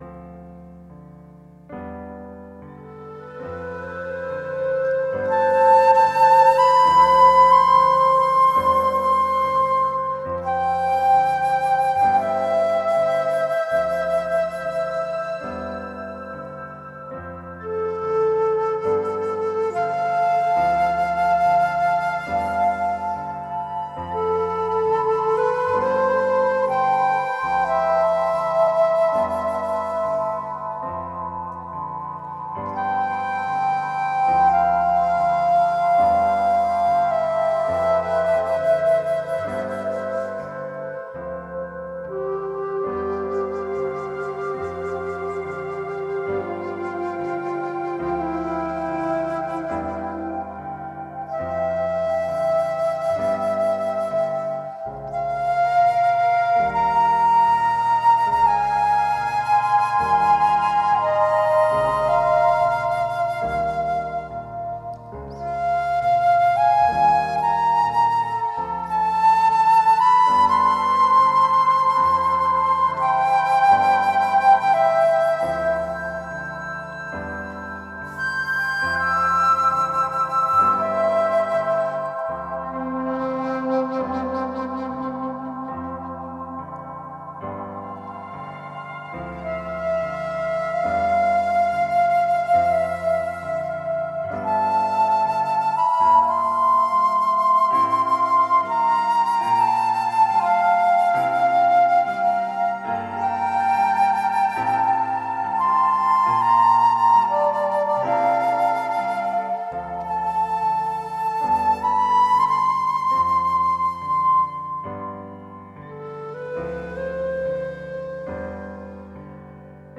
Thoughtful expressions through intelligent new age.
Tagged as: New Age, Ambient